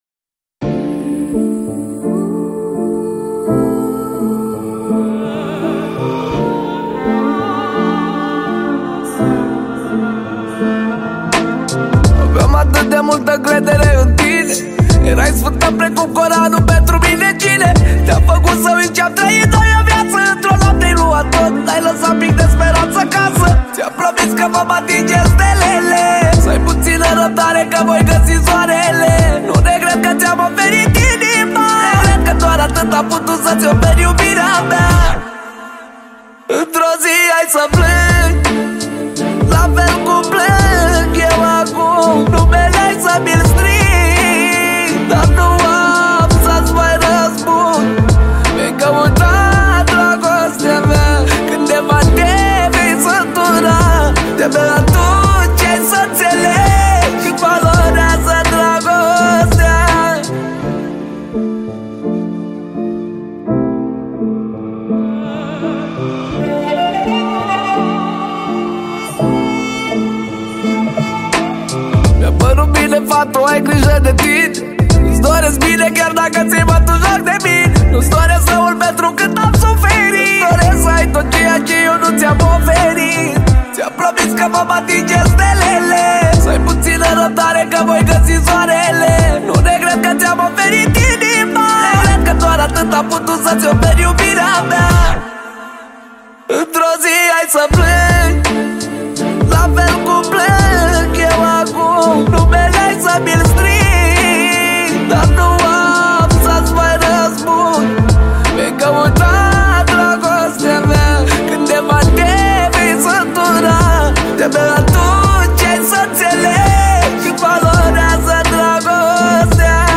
Data: 11.10.2024  Manele New-Live Hits: 0